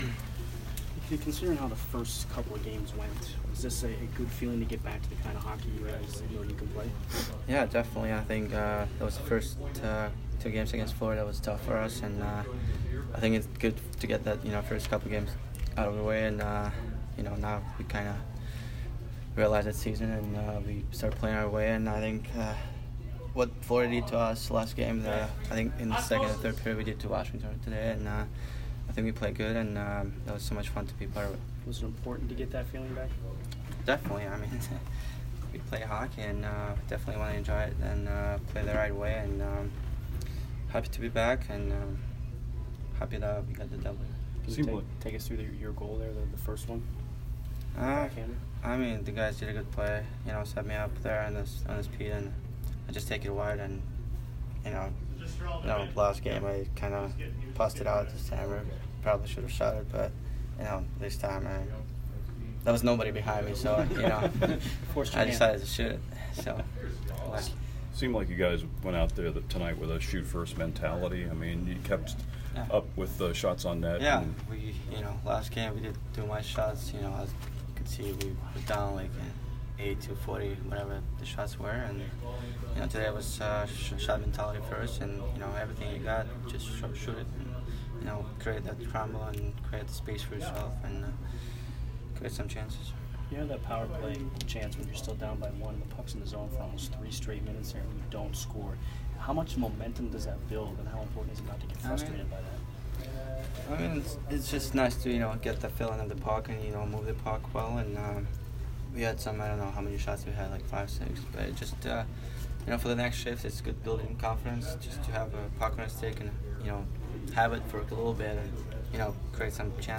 Nikita Kucherov Post Game 10/9